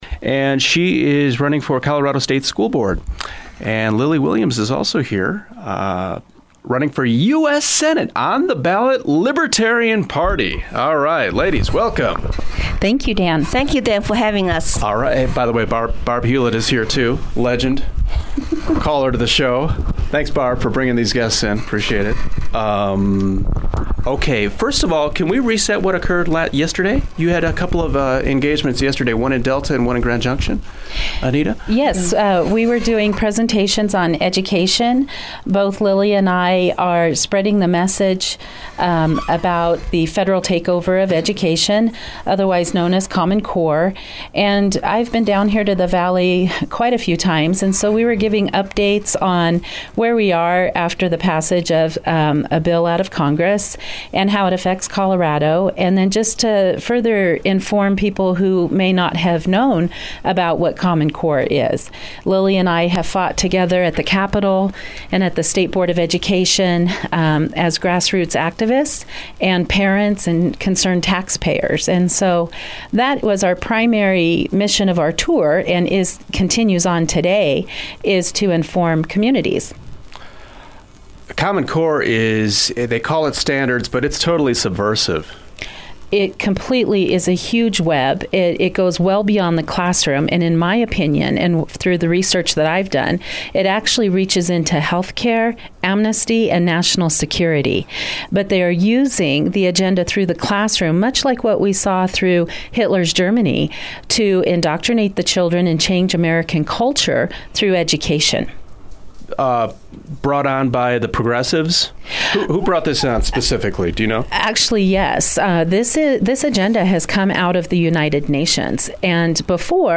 Common Core Interview